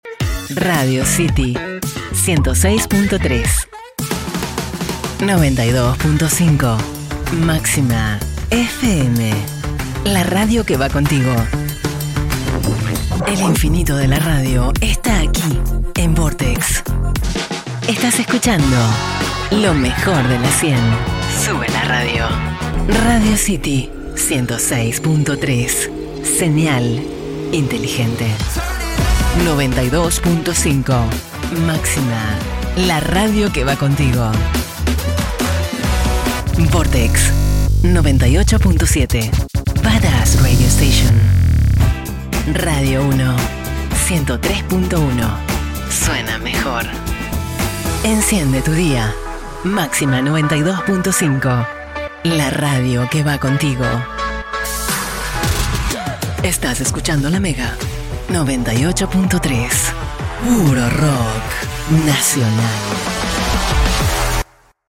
Demo Reel Radio Imaging 2024 (Cuñas/ Separadores)
Empezamos el 2024 con un repaso de cuñas que he tenido el gusto de escribir, interpretar y editar.
demo-reel-radio-imaging-2024-cunas-separadores.mp3